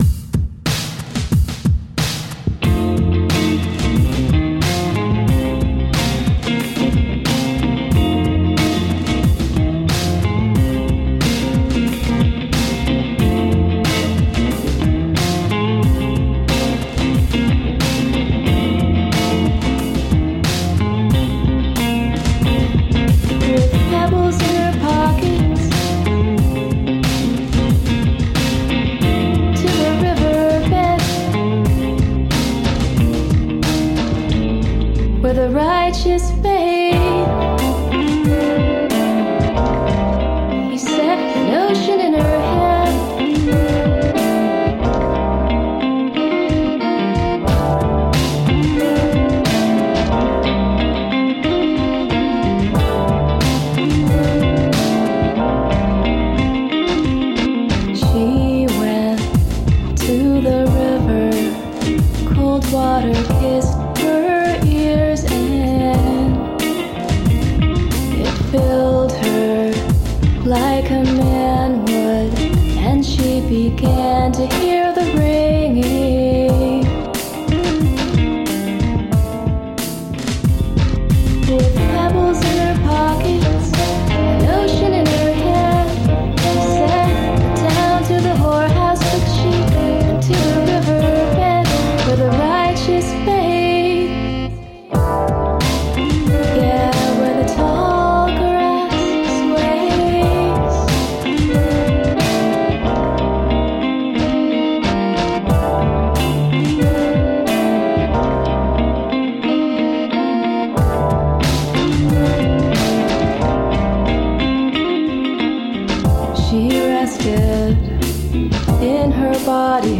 Groove soaked ambient chill.
Alt Rock, Rock, Happy Hour, Remix